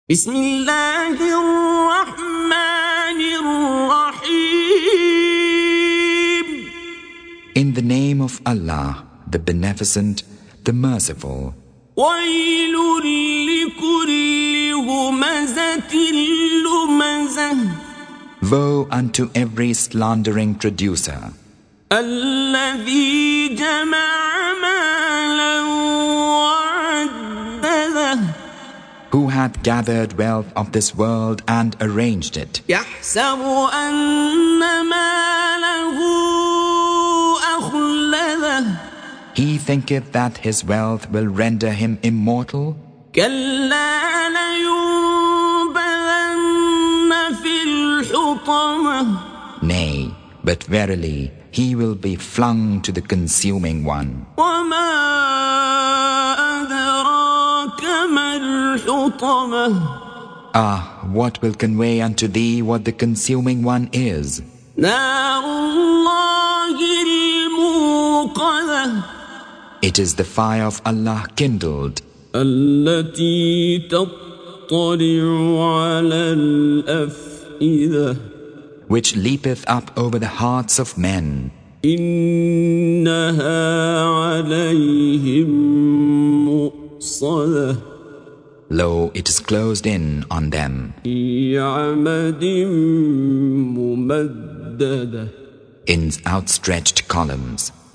Surah Sequence تتابع السورة Download Surah حمّل السورة Reciting Mutarjamah Translation Audio for 104. Surah Al-Humazah سورة الهمزة N.B *Surah Includes Al-Basmalah Reciters Sequents تتابع التلاوات Reciters Repeats تكرار التلاوات